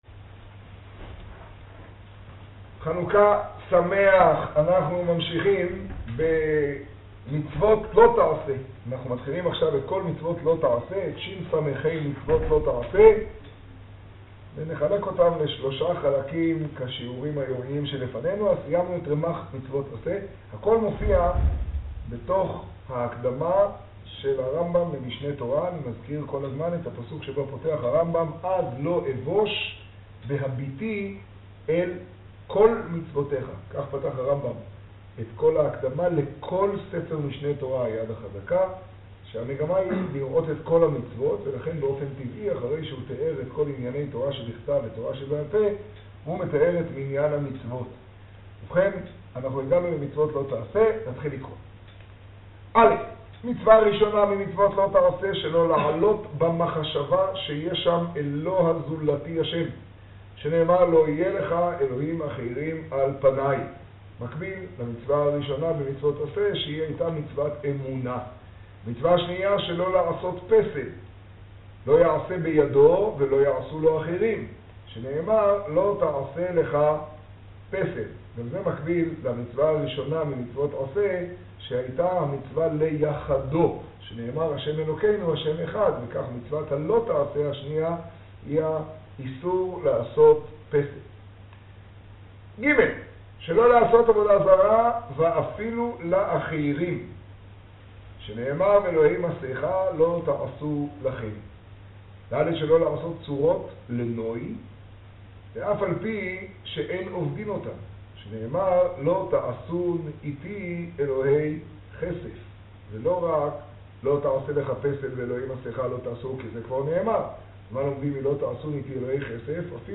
השיעור במגדל, כט כסלו תשעה.